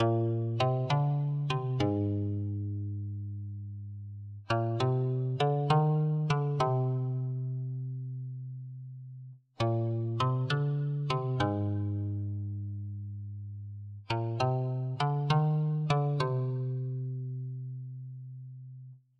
描述：深深的男声“我是偷偷摸摸的乌龟”。